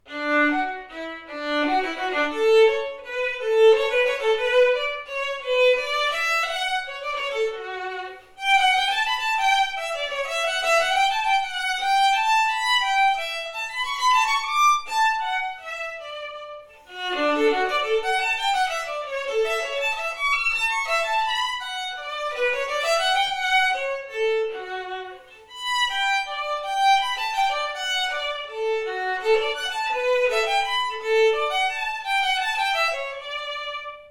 Diese führe sie solo oder mit Band-Besetzung auf.